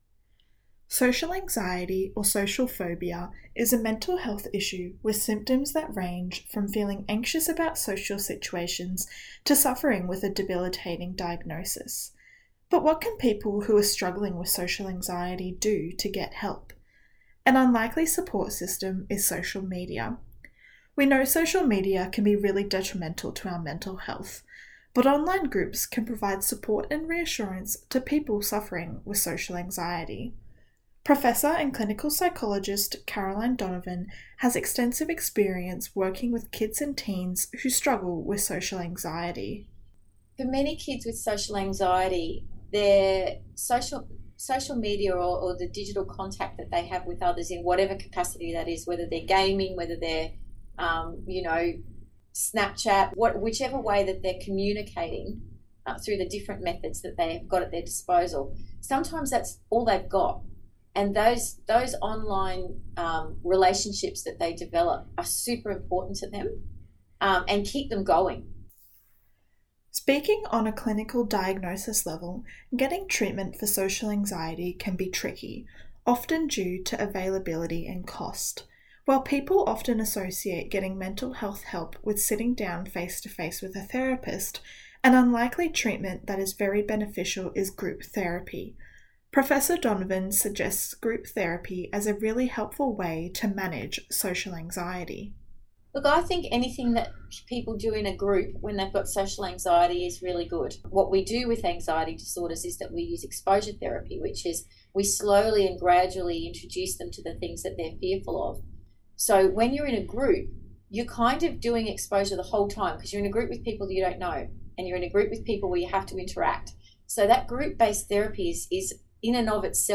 Radio-Package_social-anxiety.mp3